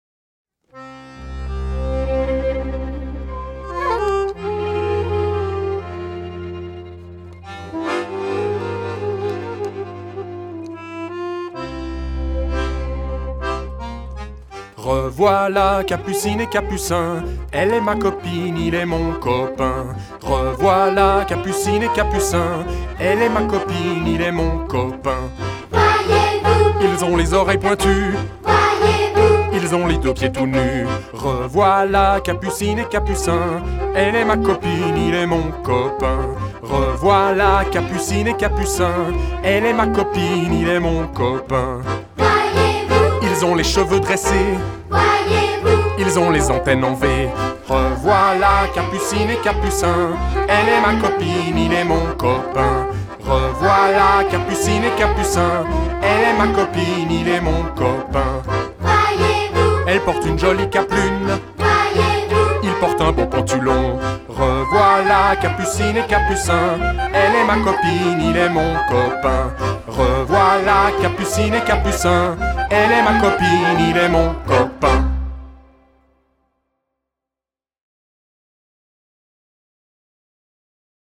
Les revoilà ! Danse tzigane pour enfants - LaZwalla - Danse
Danse tzigane pour enfants avec mouvements simples, chorégraphies ludiques, musique entraînante et variantes créatives adaptées aux âges différents